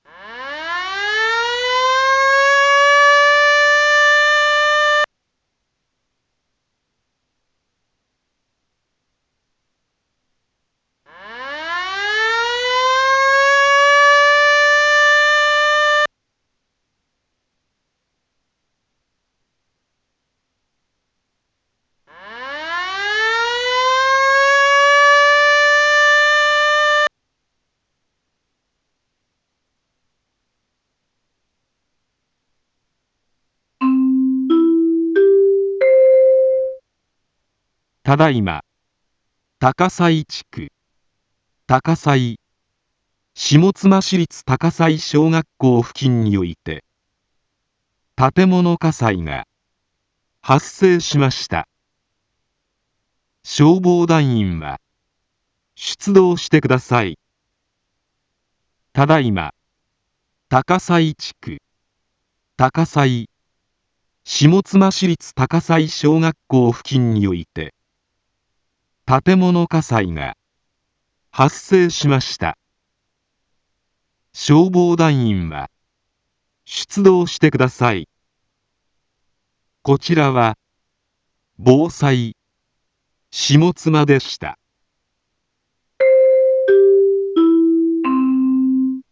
一般放送情報
Back Home 一般放送情報 音声放送 再生 一般放送情報 登録日時：2024-05-18 17:02:33 タイトル：火災報 インフォメーション：ただいま、高道祖地区、高道祖、下妻市立高道祖小学校 付近において、 建物火災が、発生しました。 消防団員は、出動してください。